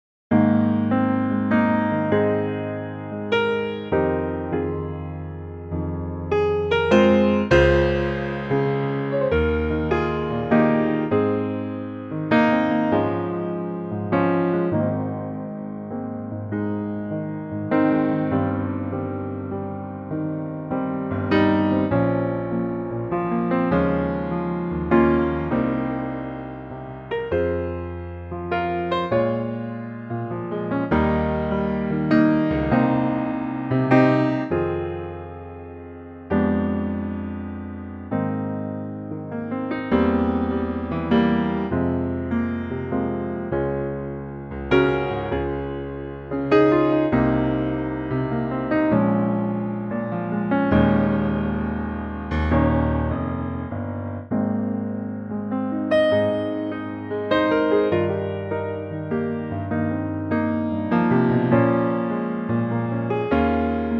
Unique Backing Tracks
key - Ab to Bb - vocal range - Ab to D
Gorgeous piano only arrangement